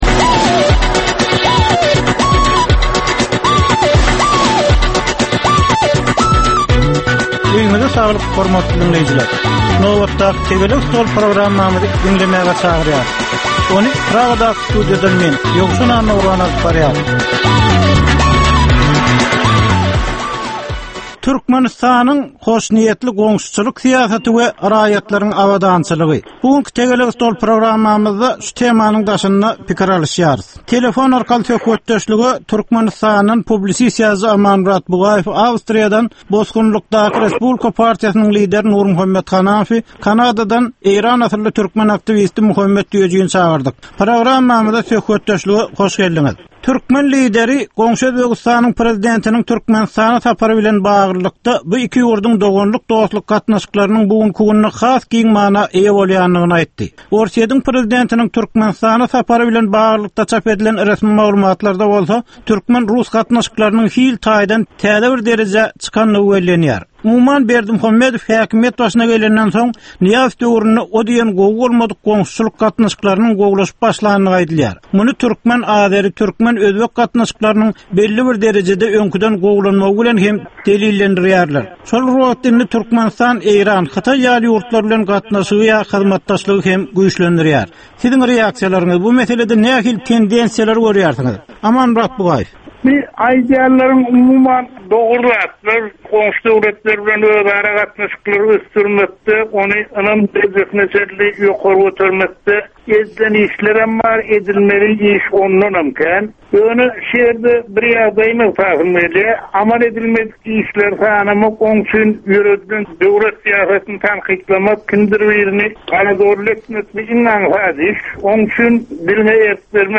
Jemgyýetçilik durmuşynda bolan ýa-da bolup duran soňky möhum wakalara ýa-da problemalara bagyşlanylyp taýýarlanylýan ýörite “Tegelek stol” diskussiýasy. Bu gepleşikde syýasatçylar, analitikler we synçylar anyk meseleler boýunça öz garaýyşlaryny we tekliplerini orta atýarlar.